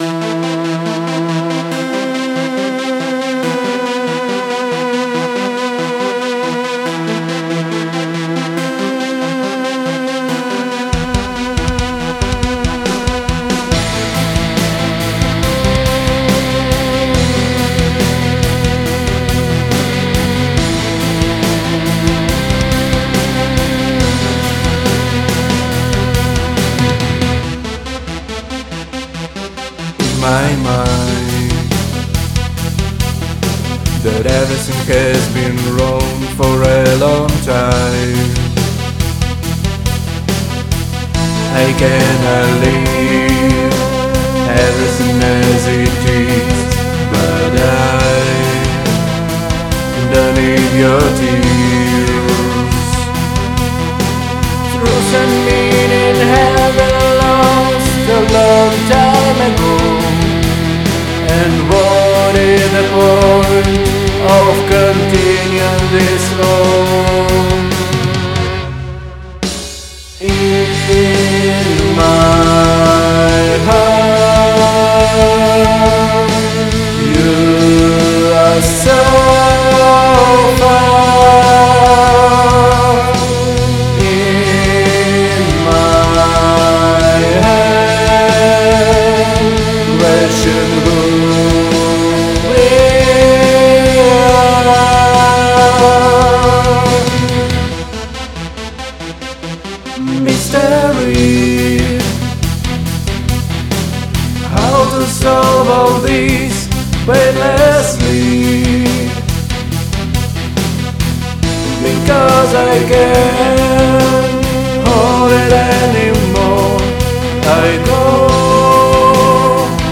Тенор Баритон